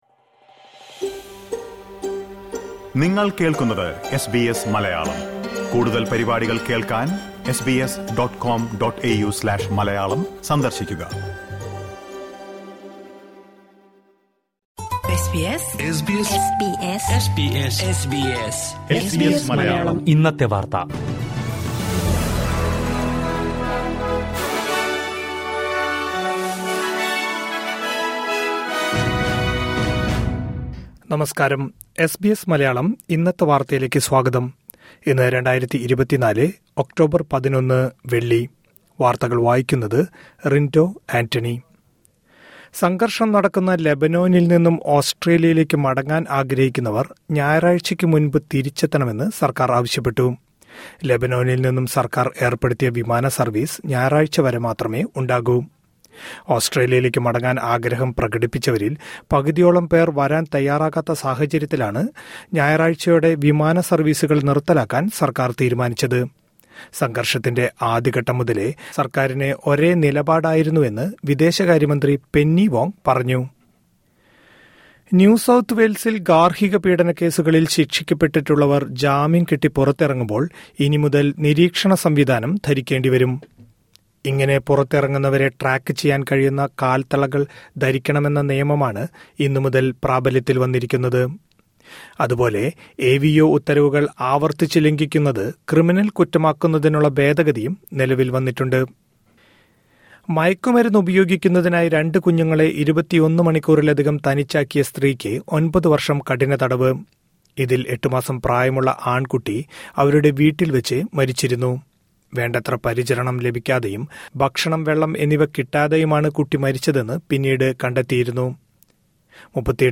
2024 ഒക്ടോബര്‍ 11ലെ ഓസ്‌ട്രേലിയയിലെ ഏറ്റവും പ്രധാന വാര്‍ത്തകള്‍ കേള്‍ക്കാം...